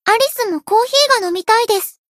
贡献 ） 分类:蔚蓝档案 ； 分类:蔚蓝档案语音 ；协议：Copyright 您不可以覆盖此文件。